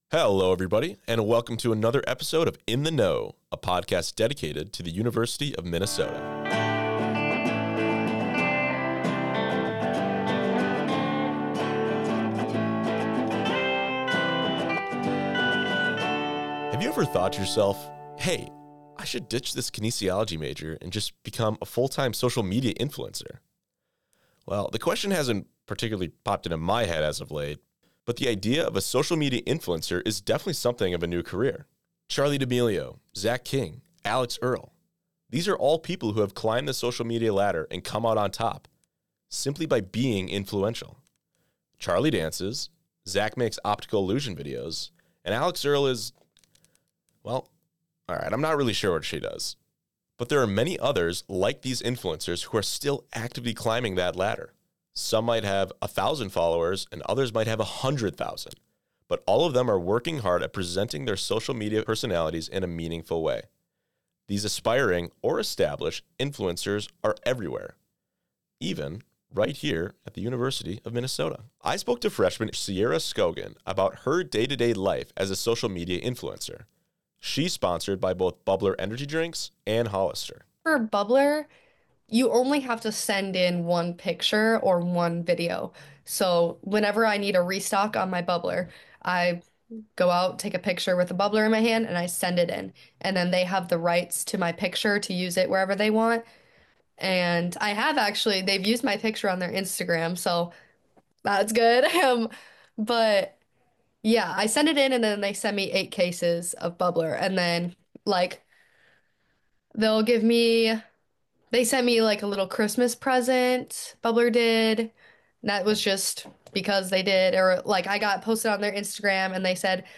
From free energy drinks to viral TikTok fame, two students share how they balance brand deals, community building and campus life as social media influencers.